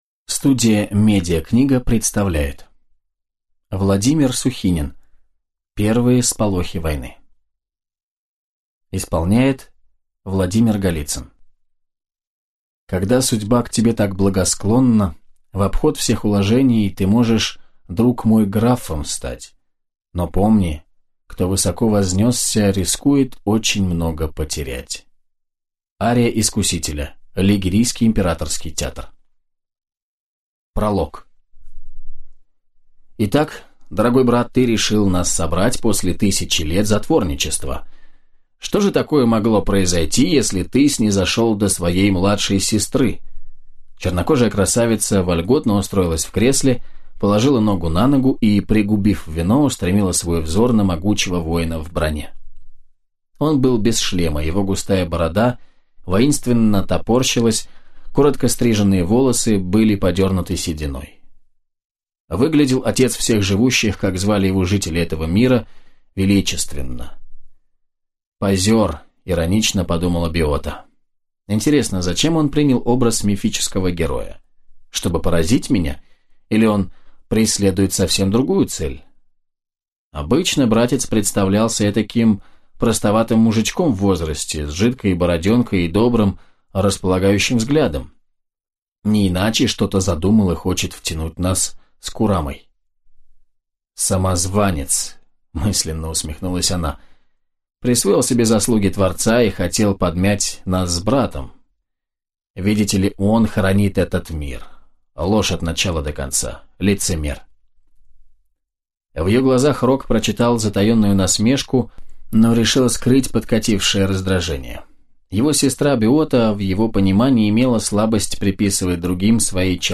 Аудиокнига Первые сполохи войны | Библиотека аудиокниг